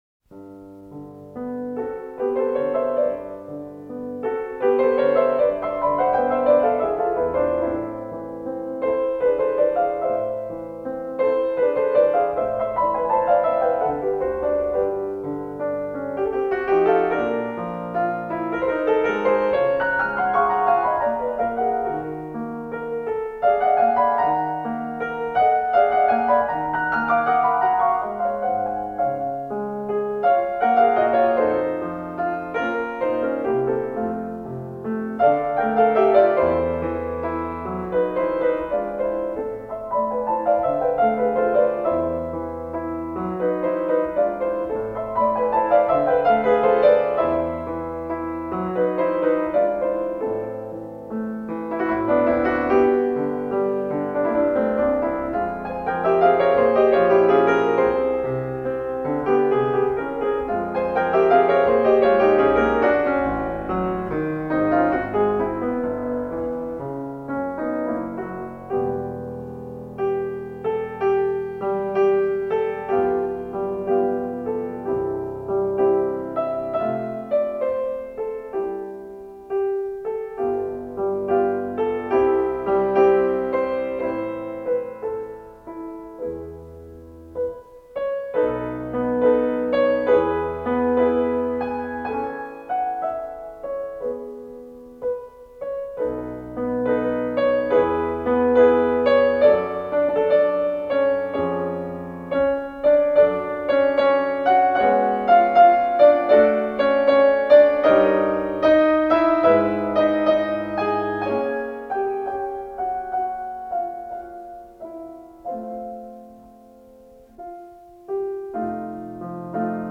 Simon, Rita J., aranžētājs
Instrumentāls
Mūzikas ieraksts